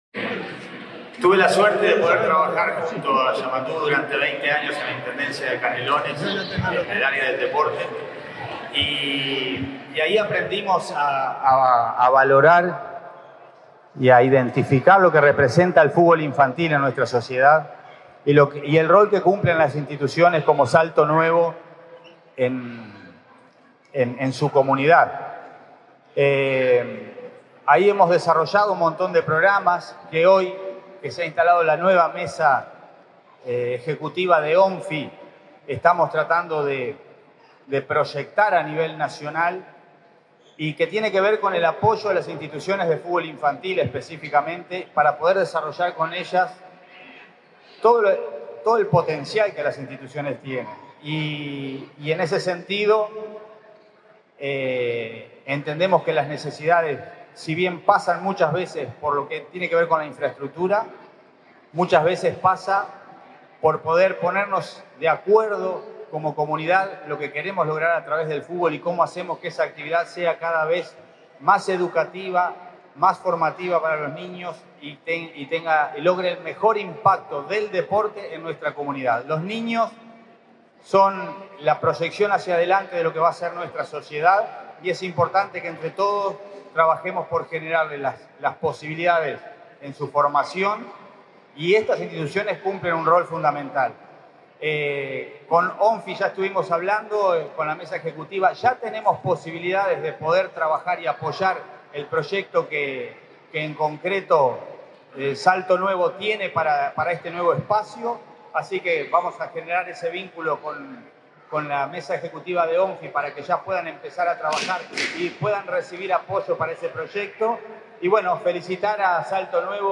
Palabras del secretario nacional del Deporte, Alejandro Pereda
El secretario nacional del Deporte, Alejandro Pereda, participó en el acto de entrega en comodato de un predio de la ANEP al club Salto Nuevo, en el